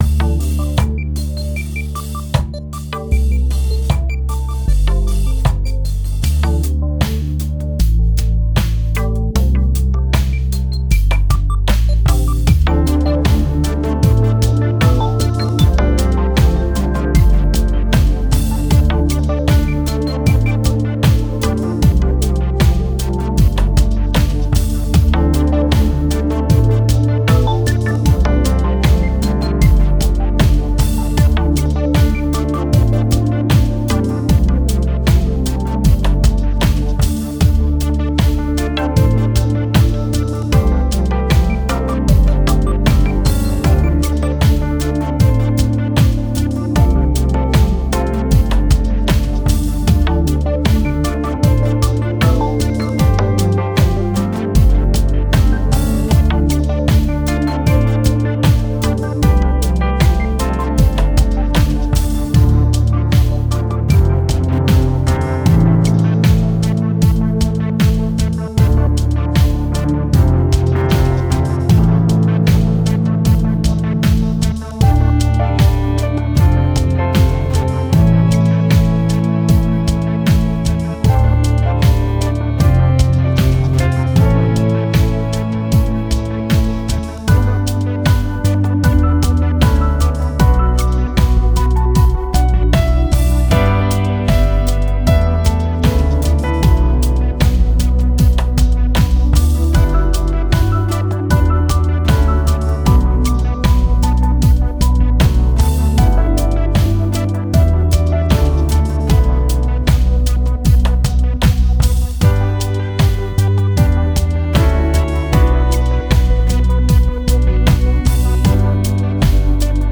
An ambient electronic track